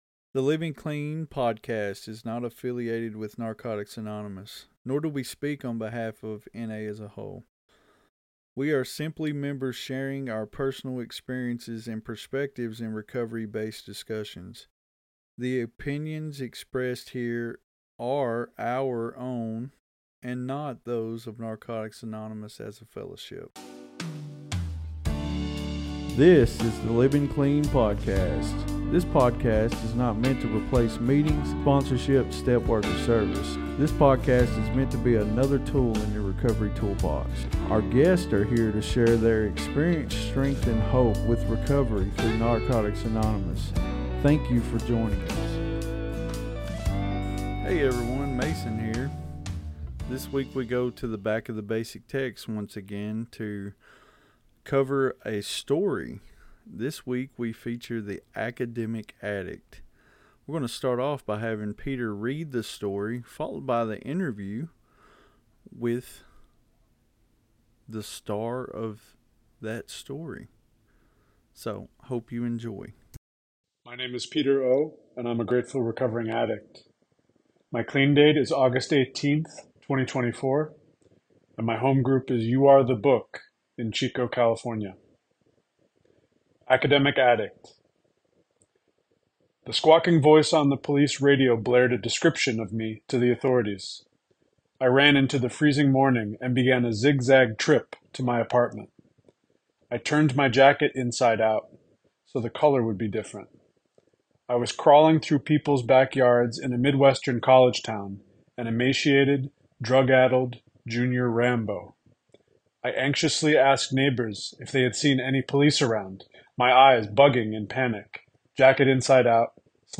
This episode is a testament to the transformative power of recovery and service. Tune in for an unforgettable conversation!